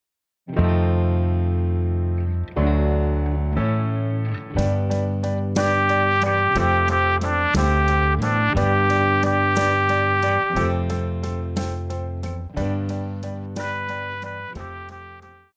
Pop
Trumpet
Band
Classics,POP,Ballad
Instrumental
Rock,Ballad
Only backing